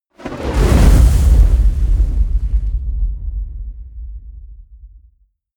Gemafreie Sounds: Explosionen